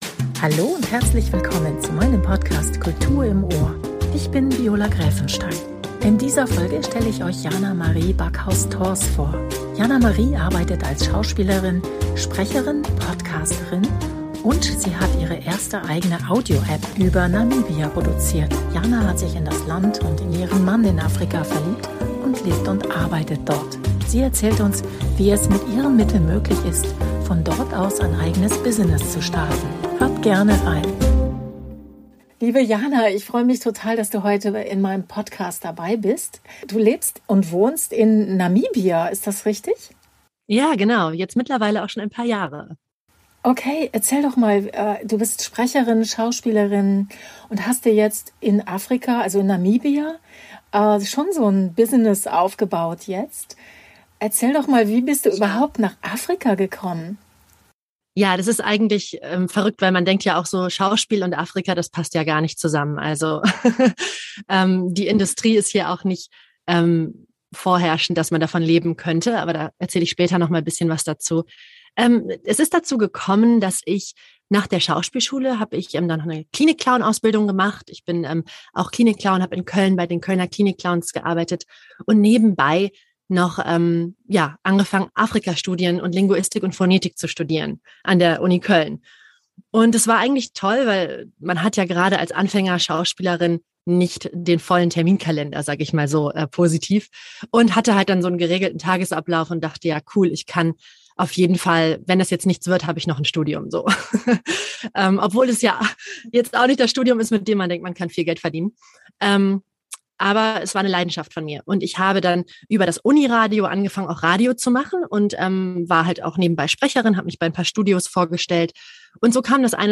Dazu kommen Menschen aus Kunst und Kultur zu Wort, die ganz eigene Wege gehen und etwas zum Thema erfolgreiche Selbstvermarktung und Kreativität beitragen können.